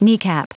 Prononciation du mot kneecap en anglais (fichier audio)
Prononciation du mot : kneecap